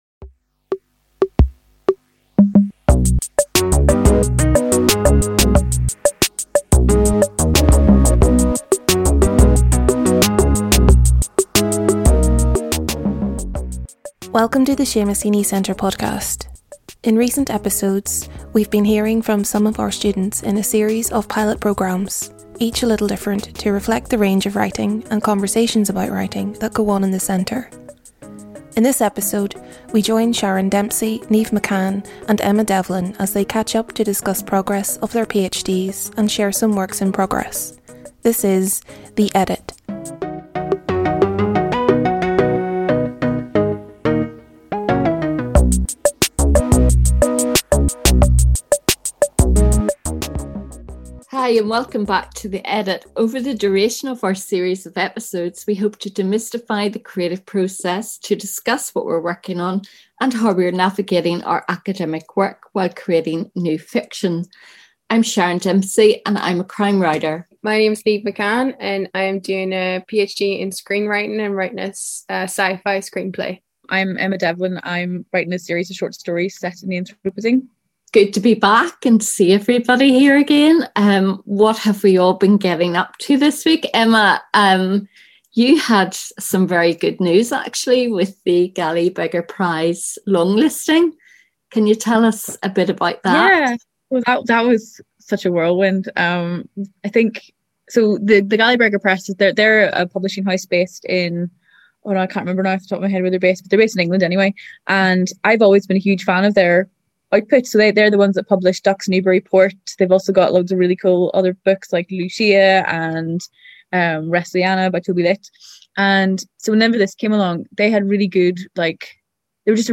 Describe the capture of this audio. The Seamus Heaney Centre Podcast is created in a small back room